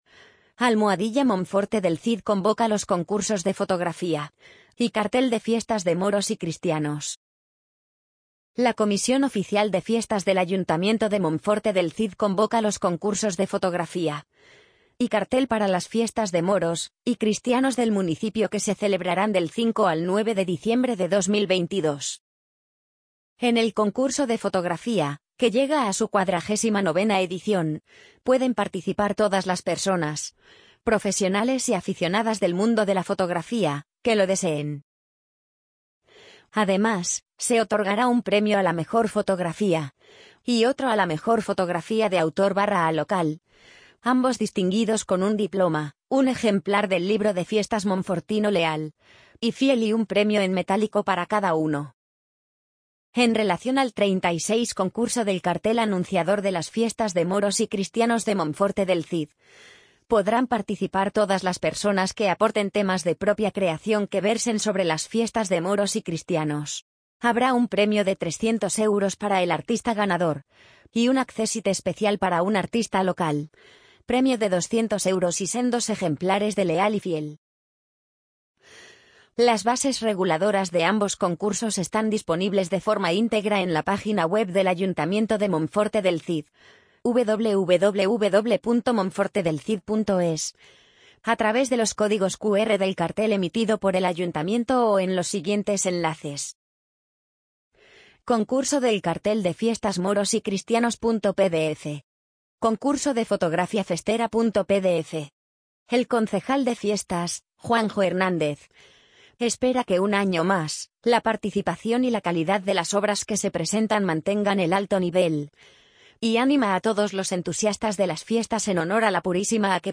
amazon_polly_59101.mp3